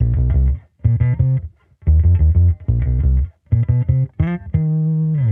Index of /musicradar/sampled-funk-soul-samples/90bpm/Bass
SSF_PBassProc2_90C.wav